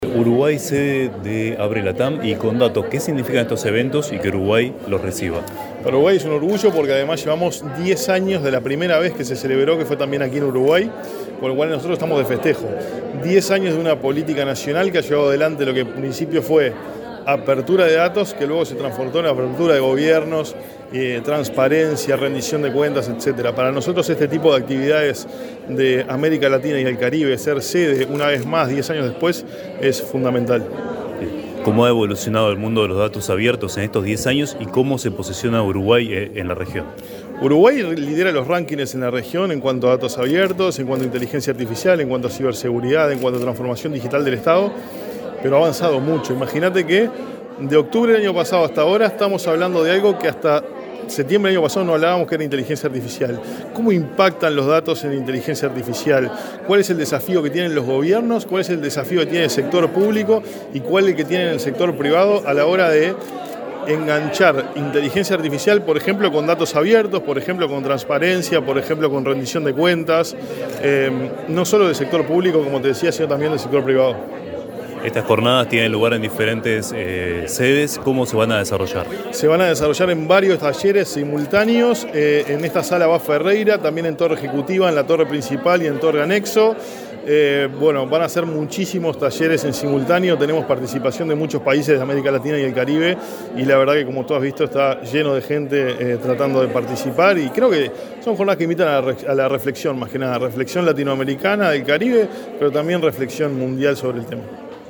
Entrevista al director ejecutivo de Agesic, Hebert Paguas